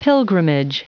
Prononciation du mot pilgrimage en anglais (fichier audio)
Prononciation du mot : pilgrimage
pilgrimage.wav